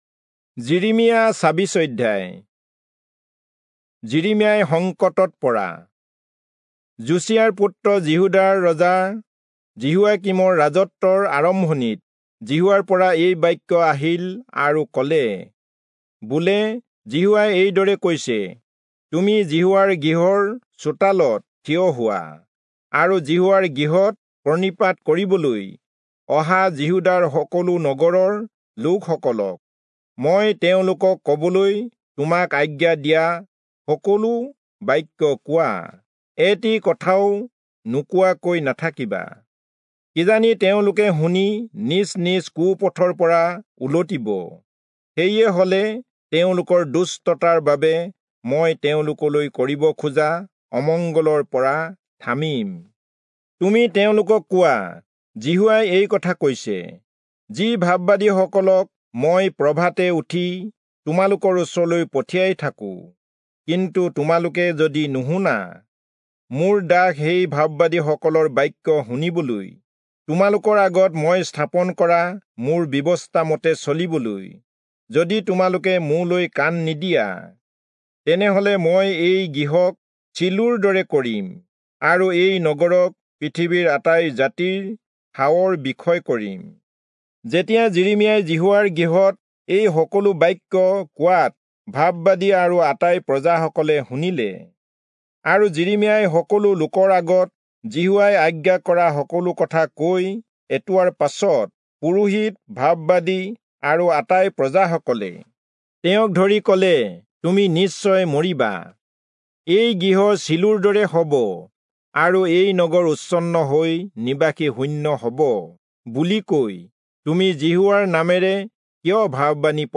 Assamese Audio Bible - Jeremiah 17 in Irvas bible version